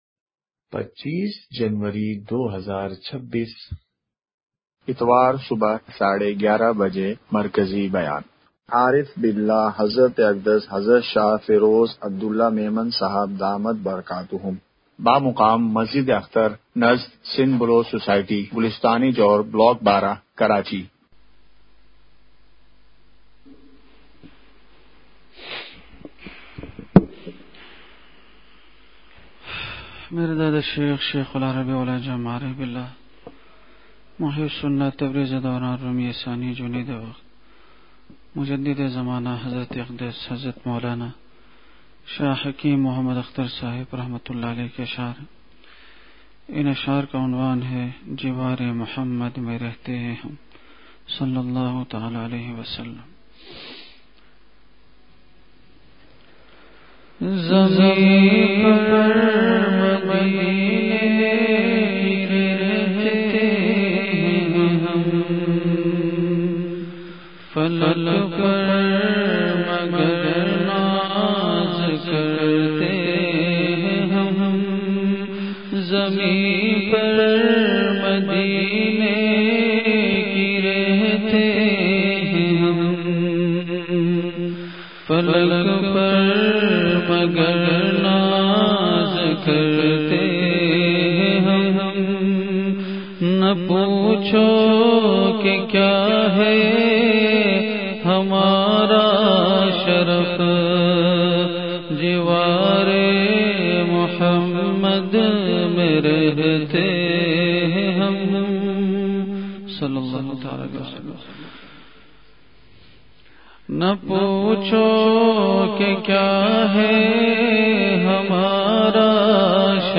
اصلاحی مجلس
*مقام:مسجد اختر نزد سندھ بلوچ سوسائٹی گلستانِ جوہر کراچی*